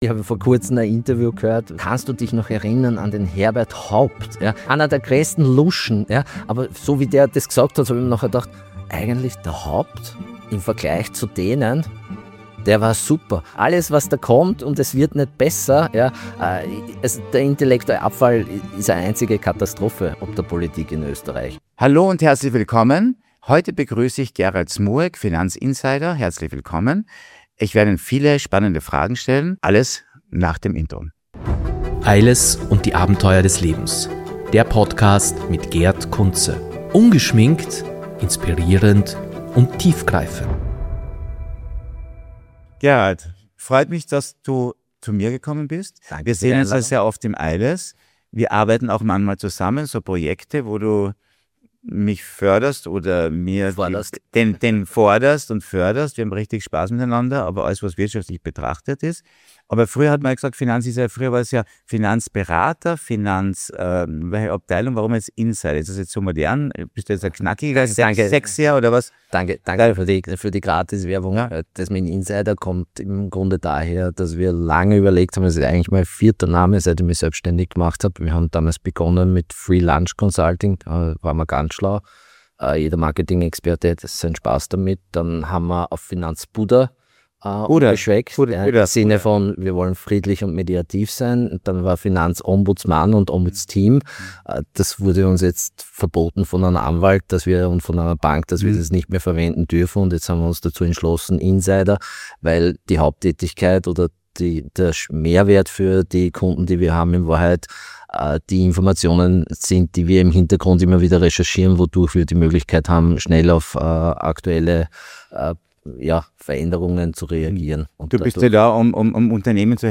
Im Gespräch geht es um politische Entscheidungen, wirtschaftliche Rahmenbedingungen, die Rolle der Wirtschaftskammer und die Frage, warum Reformen in Österreich so schwer umzusetzen sind. Ein offenes Gespräch über Wirtschaft, Politik und persönliche Erfahrungen.